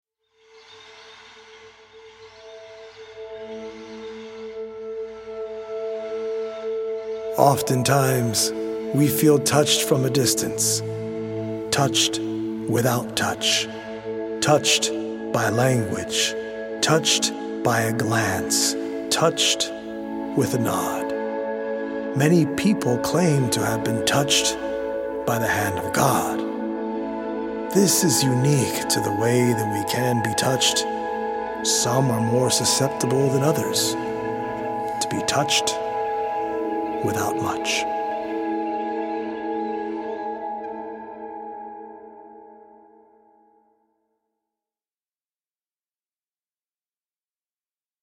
100 original poems written/performed
healing Solfeggio frequency music